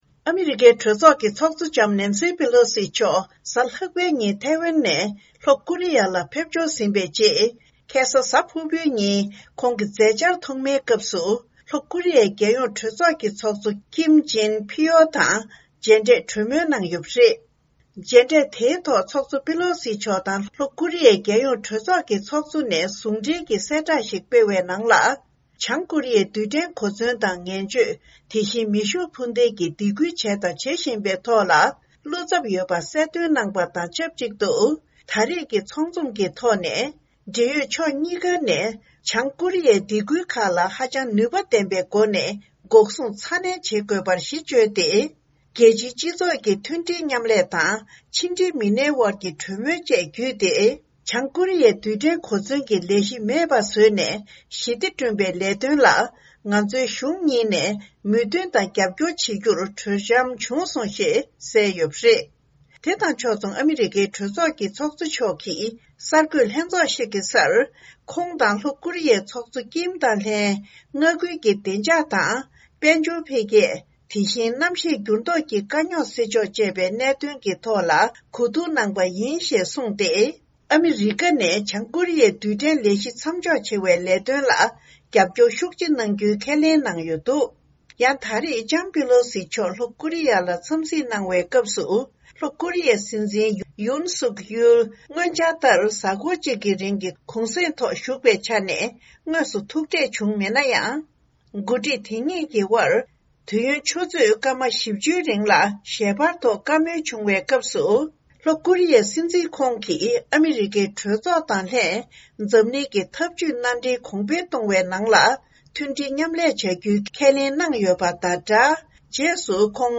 ཕྱོགས་བསྒྲིགས་དང་སྙན་སྒྲོན་ཞུ་ཡི་རེད།